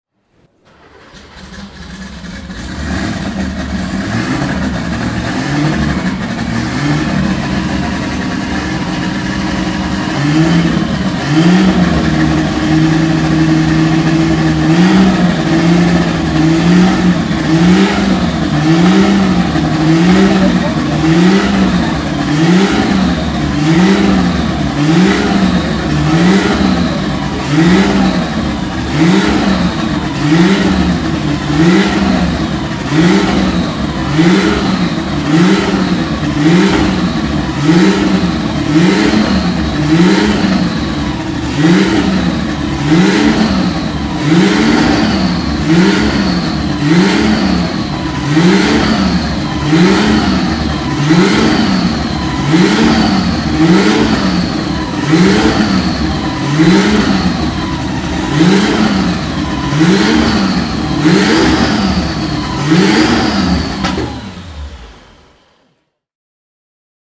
Porsche 911 Carrera RSR 3.0 (1975) - Starten und Hochdrehen an der Porsche Sound-Nacht 2013